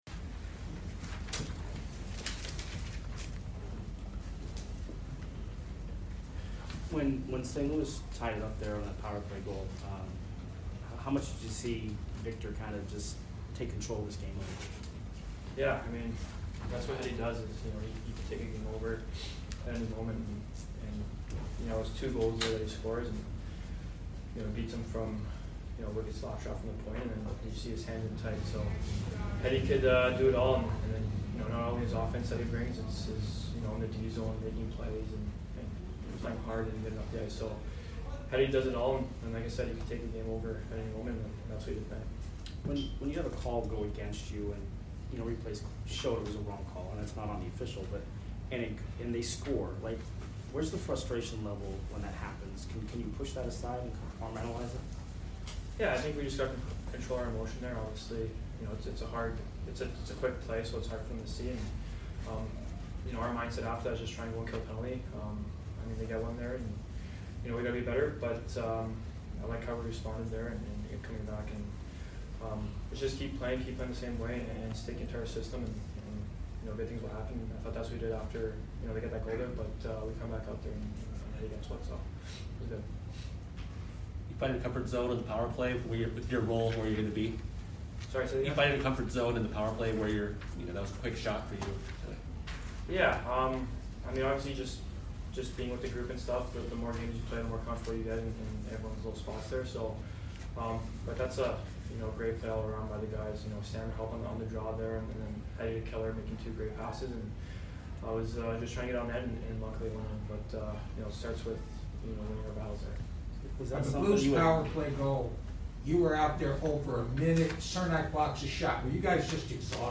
Anthony Cirelli Post Game Vs STL 12 - 2-21